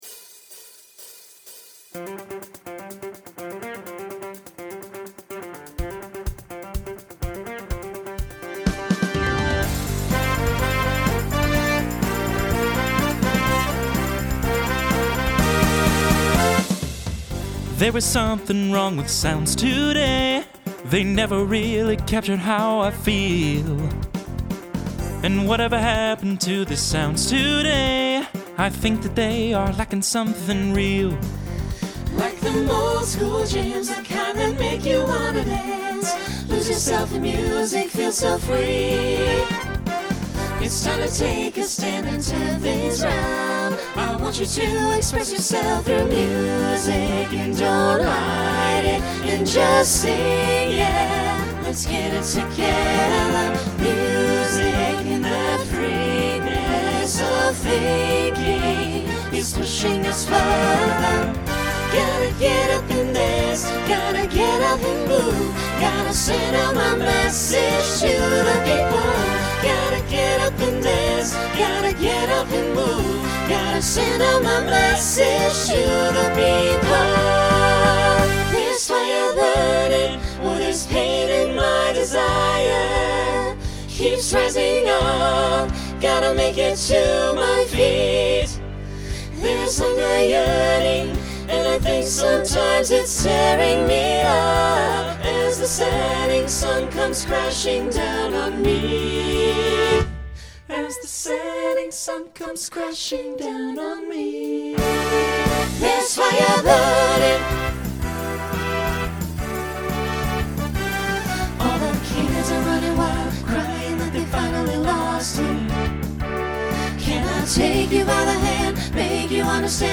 Genre Pop/Dance , Rock Instrumental combo
Voicing SATB